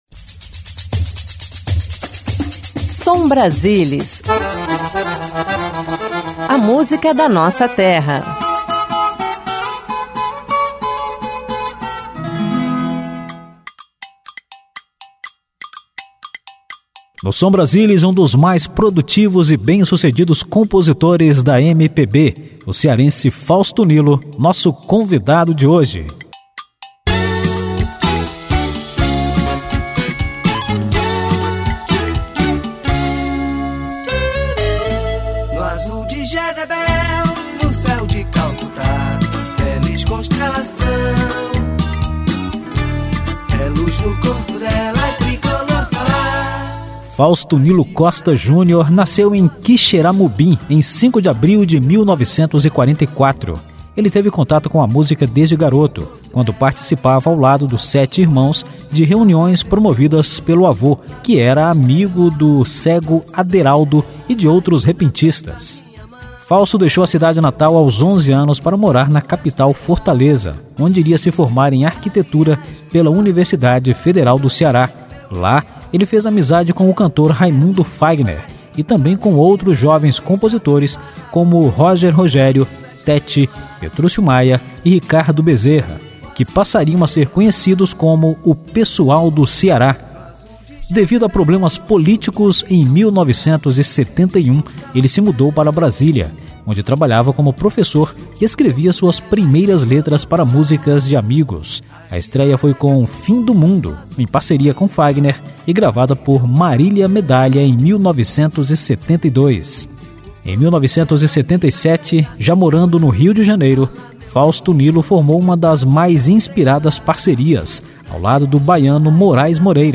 MPB Música romântica
Samba-canção